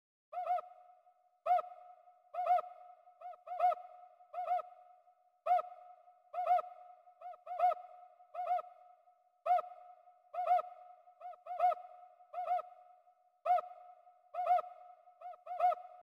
cuicalizz.mp3